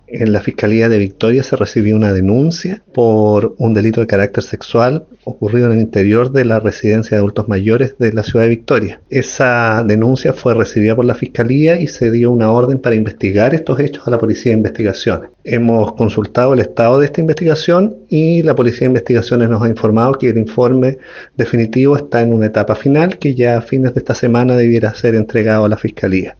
El fiscal jefe de Victoria, Héctor Leiva, señaló que se dio orden a la Policía de Investigaciones para que indague los hechos, y que se está a la espera de un informe final.
fiscal-hector-leiva.mp3